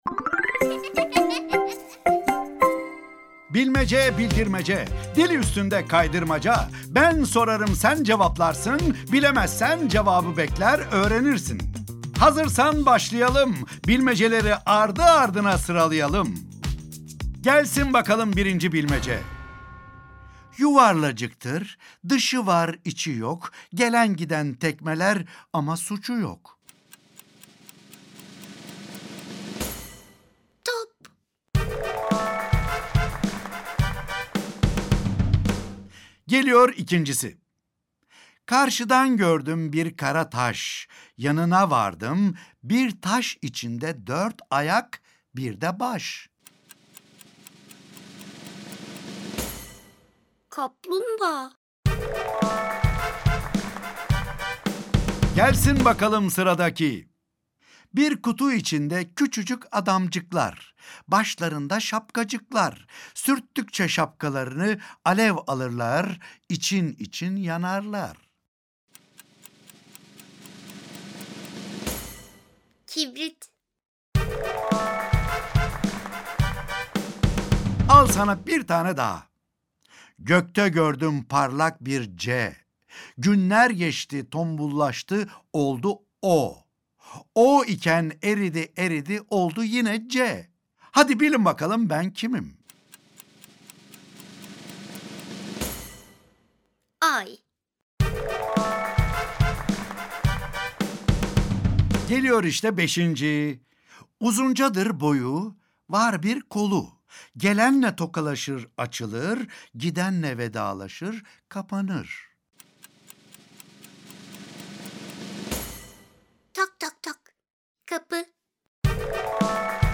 Bilmeceler-8 Tiyatrosu